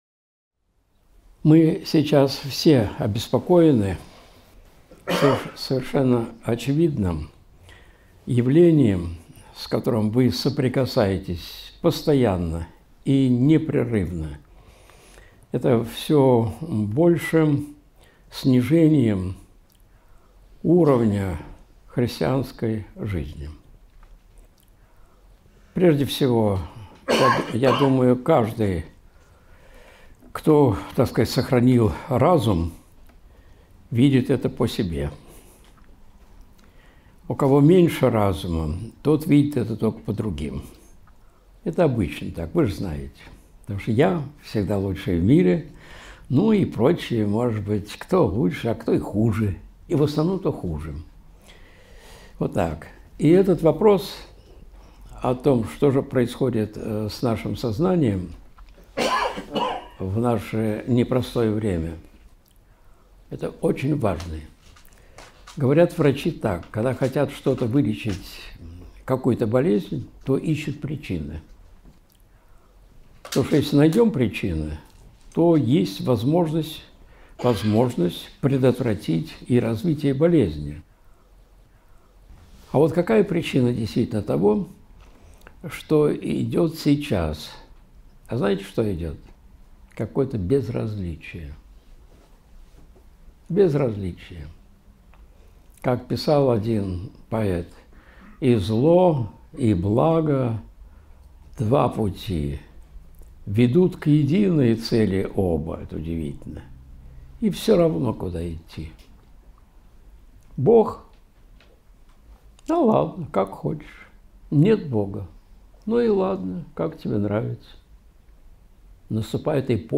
Как важно это знать! (г. Владимир. Встреча с духовенством, 12.11.2025)
Видеолекции протоиерея Алексея Осипова